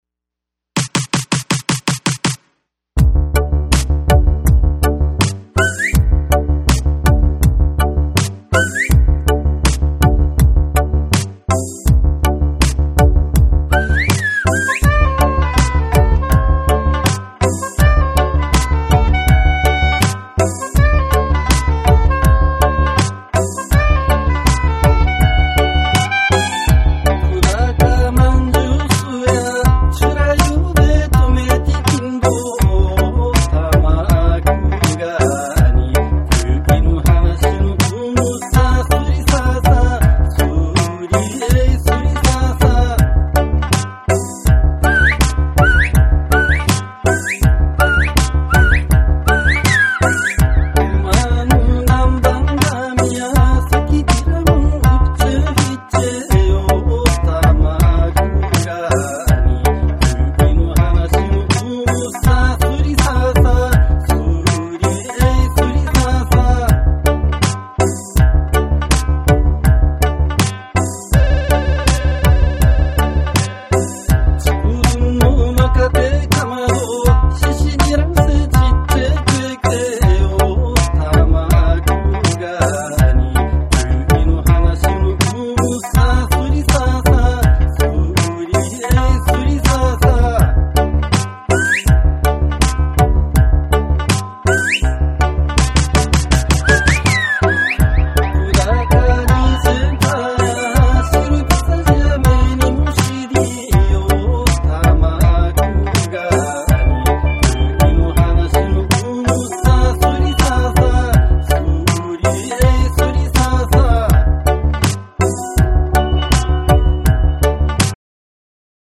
前作よりもさらにダンサブルな内容となっている。
琉球民謡に潜在するうちなーんちゅ独自のリズム感覚とジャマイカ産80年代ダンスホール・リディムの共鳴が証明された重要作。
JAPANESE / REGGAE & DUB